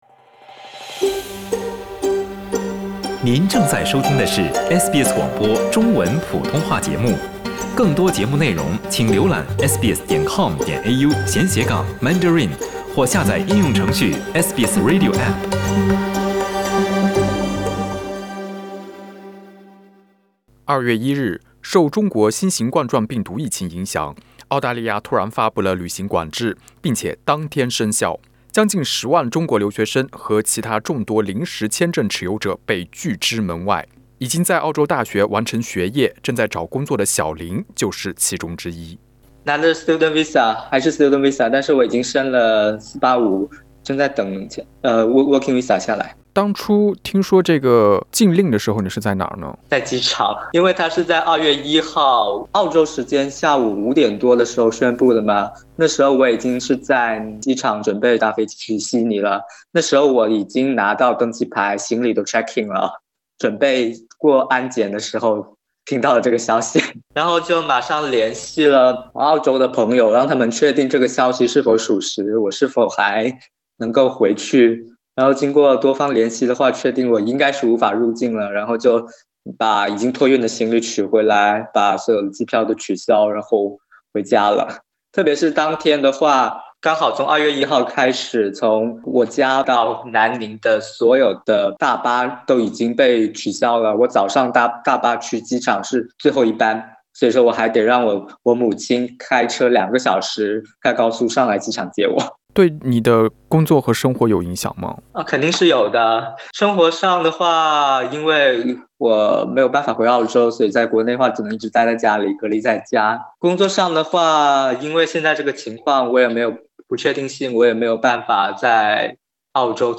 LISTEN TO 分隔两地的华人同志伴侣：疫情中过“云”上情人节 SBS Chinese 05:03 cmn *应要求使用匿名，音频经过处理。